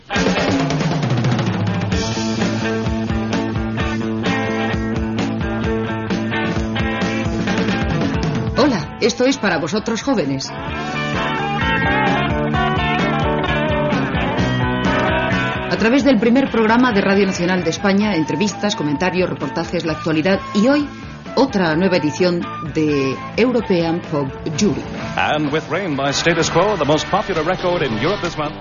Sintonia del programa i presentació d'European Pop Jury
Musical